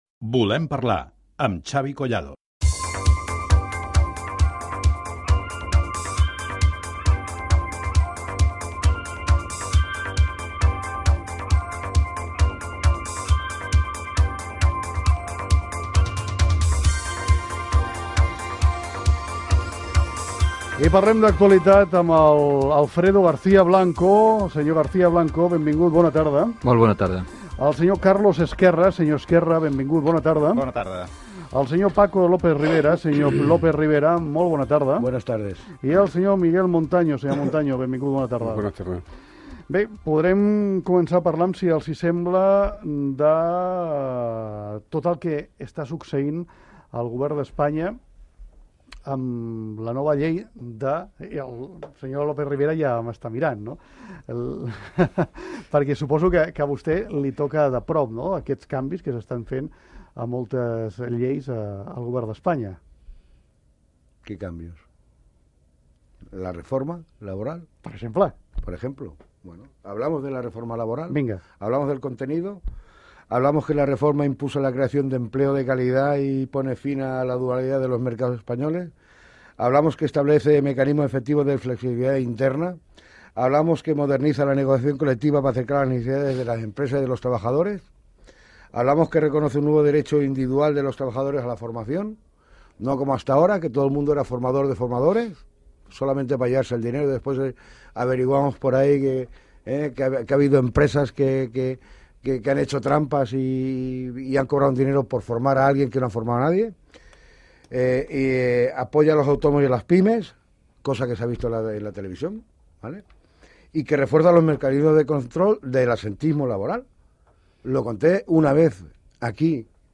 Tema: la nova llei de reforma laboral espanyola Gènere radiofònic Participació